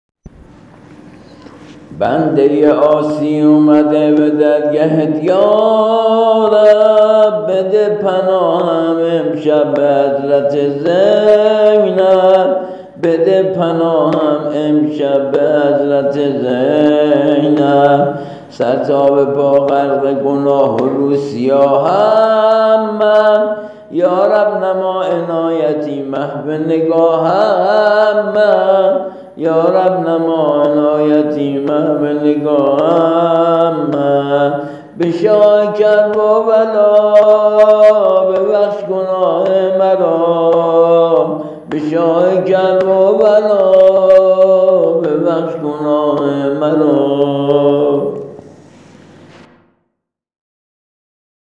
◾زمزمه مناجات